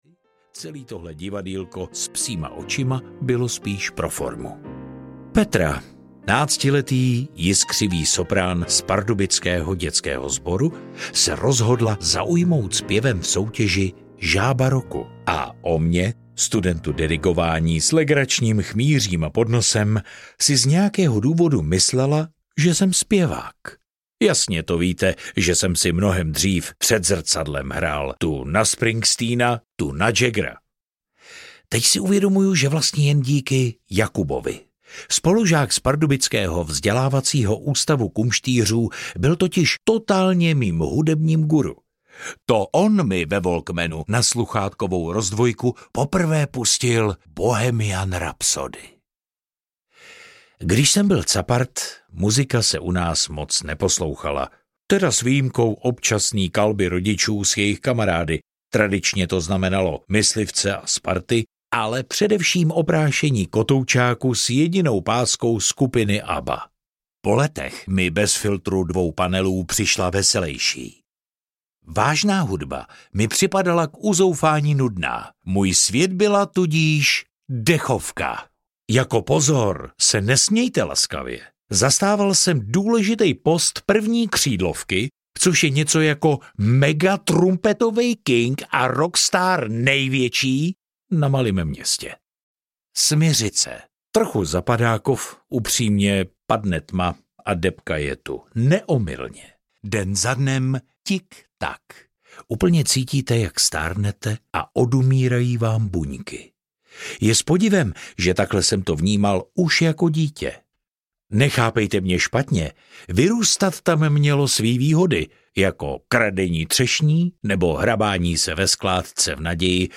Skoro všechno nej audiokniha
Ukázka z knihy
• InterpretBohdan Tůma